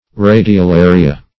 (r[=a]`d[i^]*[-o]*l[=a]"r[i^]*[.a]), n. pl. [NL. See